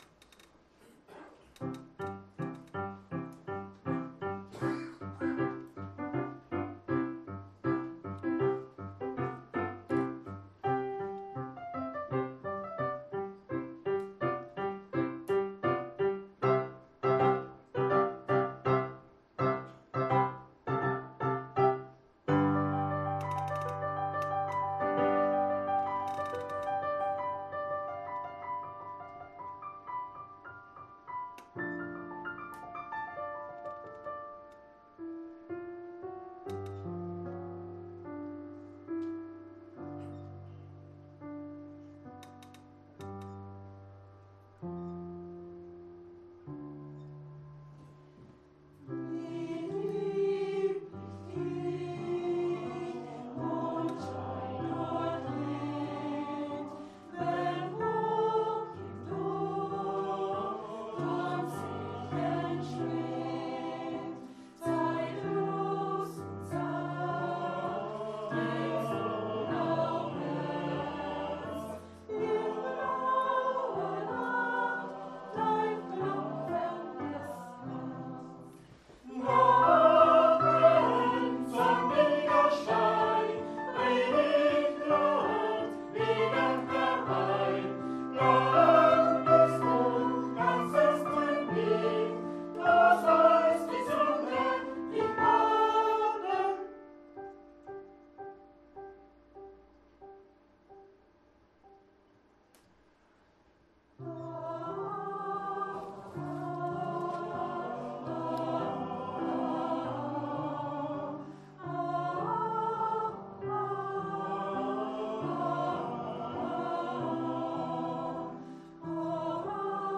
aus der Pfarrkirche Karnburg